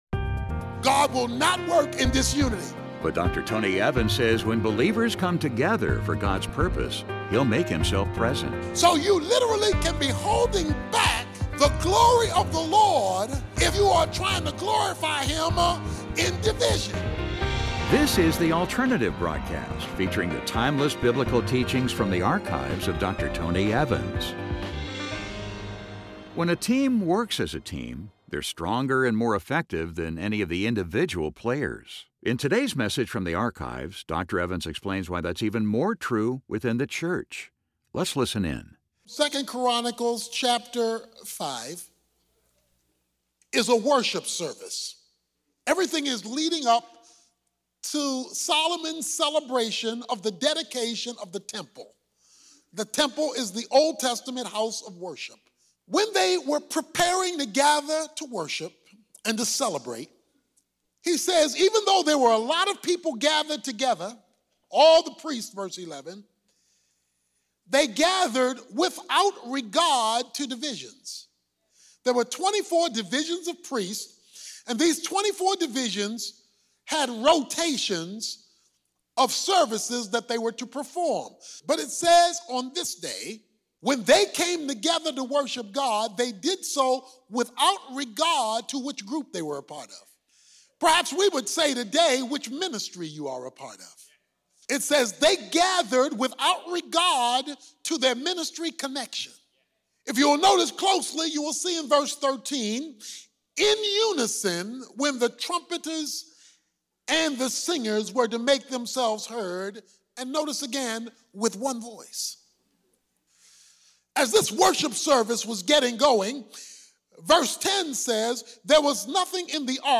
When a team works as a team, they're stronger and more effective than any of the individual players. In this message, Dr. Tony Evans explains why that's even more true within the church.